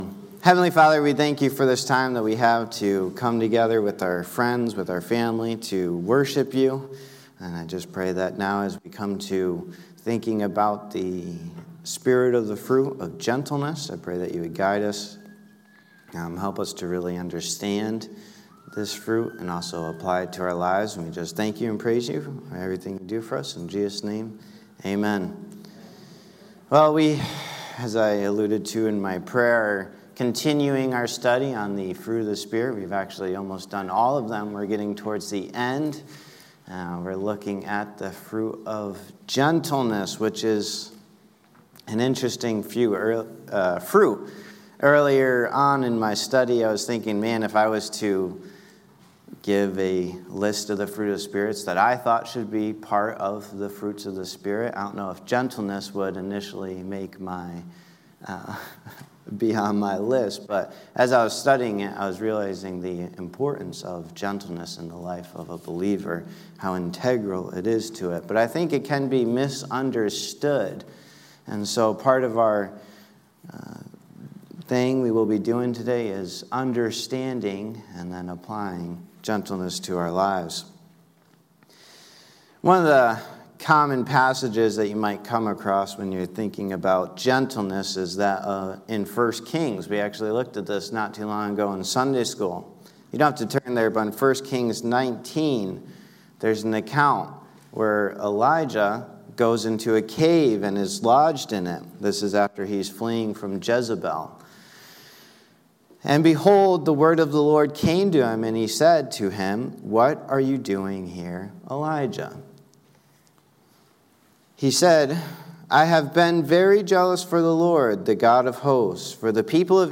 Sermons | Ellington Baptist Church